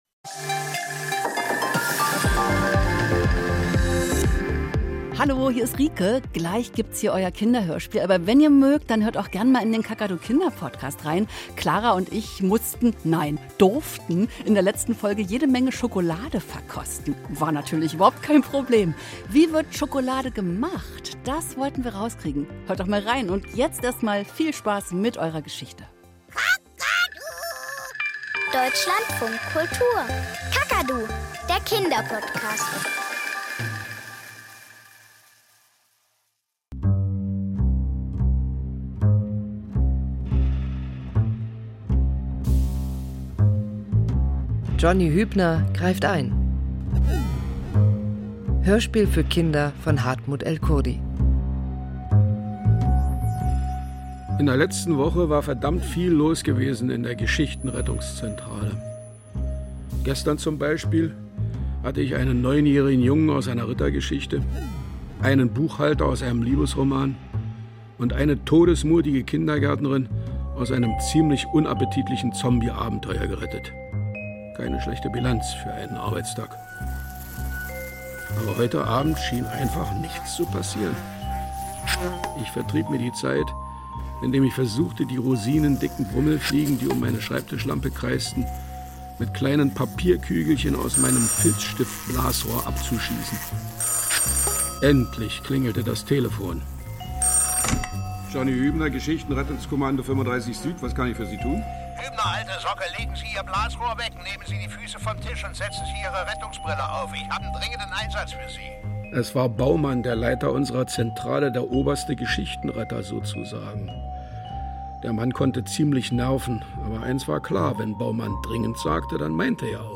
Kinderhörspiel - Johnny Hübner greift ein